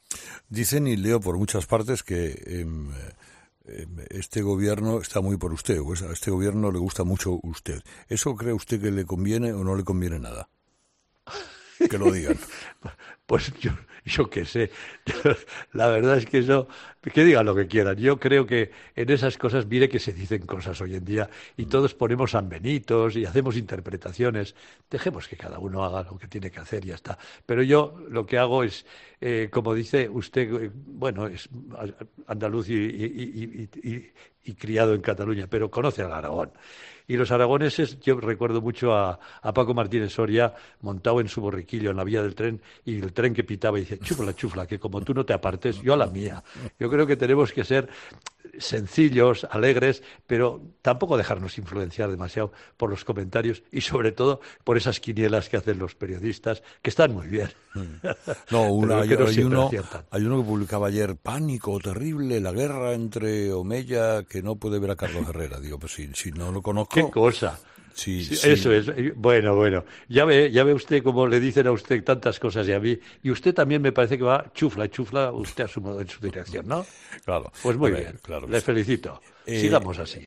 Algo de lo que ambos se han reído. "Han dicho que no le puedo ni ver. ¡Pero si no le conozco!", ha dicho entre risas Herrera.